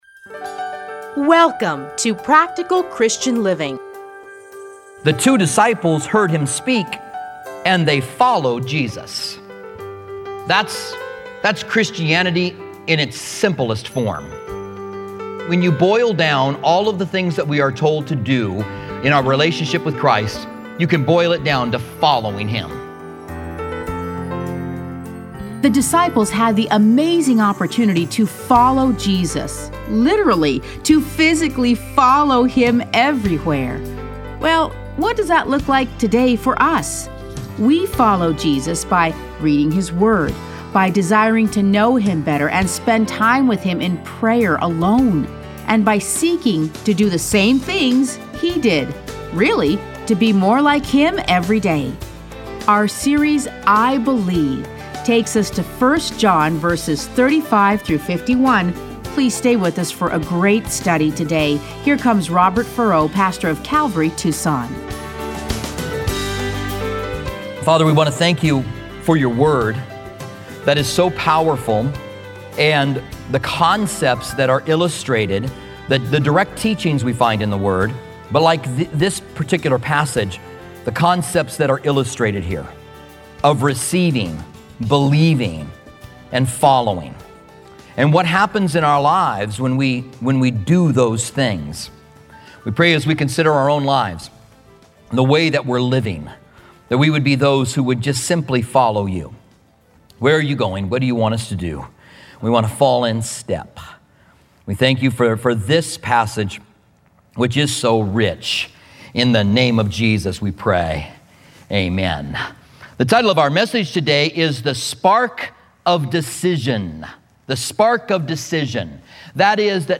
Listen to a teaching from John 1:35-51.